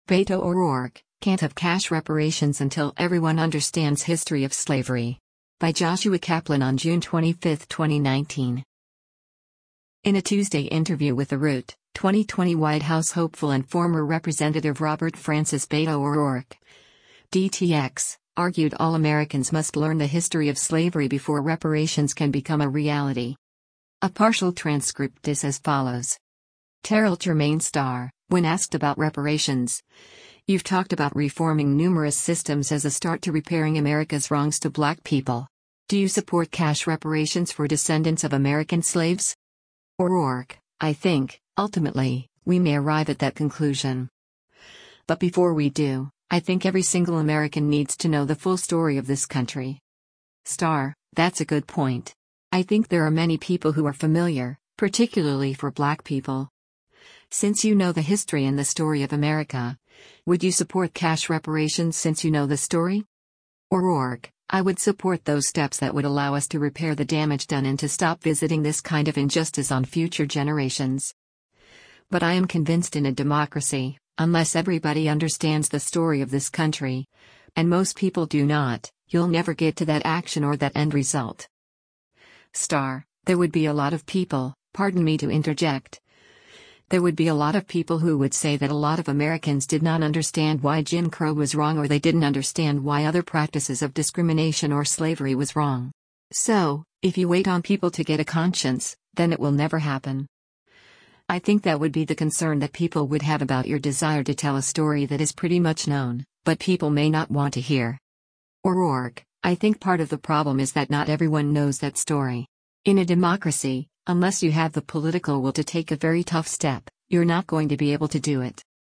In a Tuesday interview with The Root, 2020 White House hopeful and former Rep. Robert Francis “Beto” O’Rourke (D-TX) argued all Americans must learn the history of slavery before reparations can become a reality.